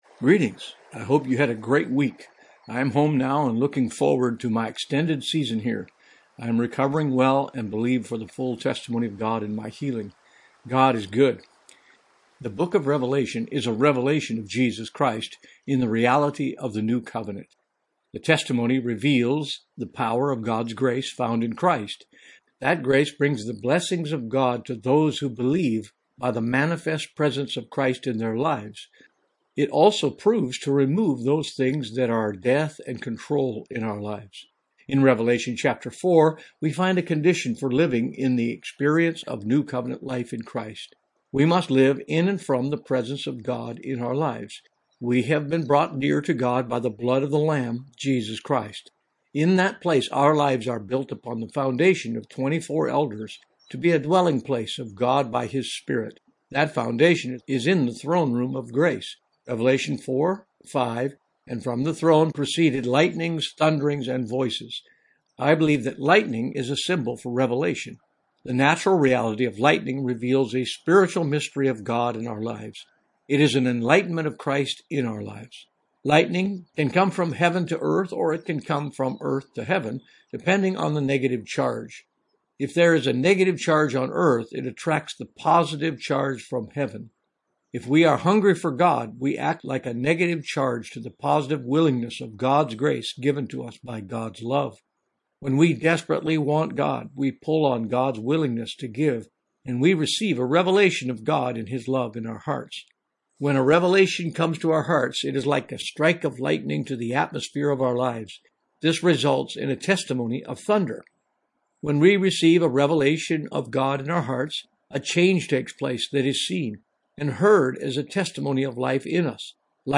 Blog In Audio: